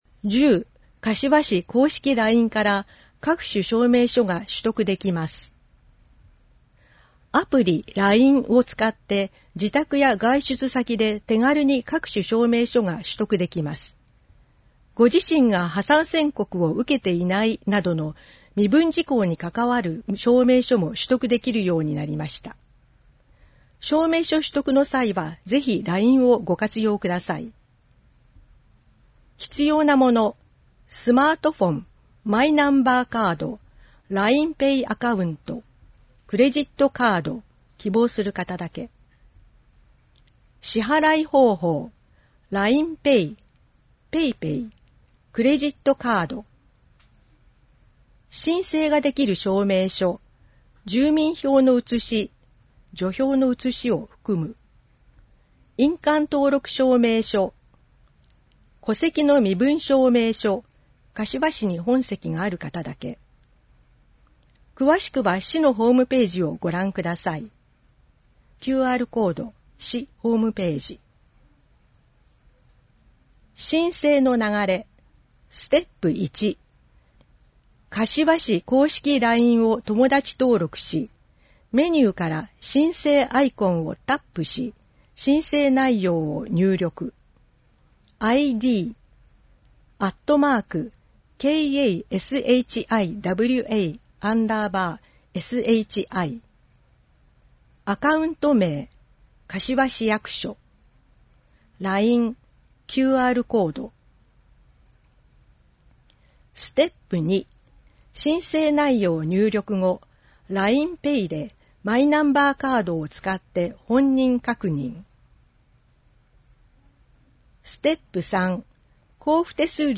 • 広報かしわの内容を音声で収録した「広報かしわ音訳版」を発行しています。
• 発行は、柏市朗読奉仕サークルにご協力いただき、毎号行っています。